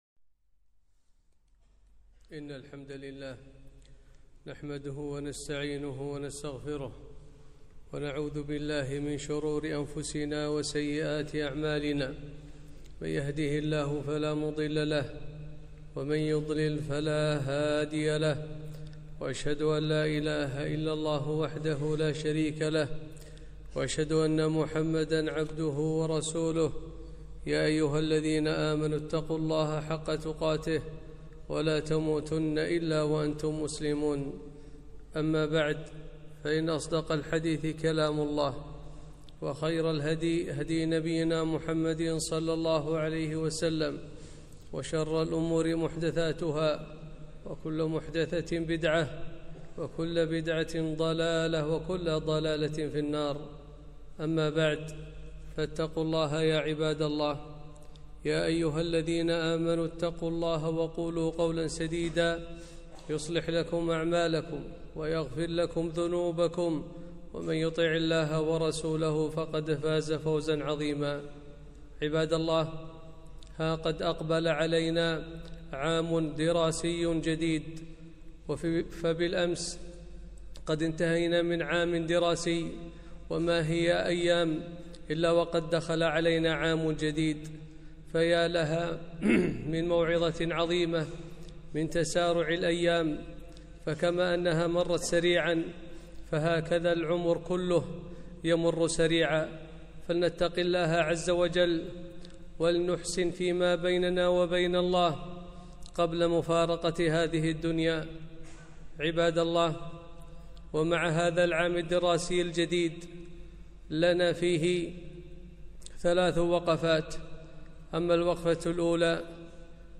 خطبة - وقفات مع عام دراسي جديد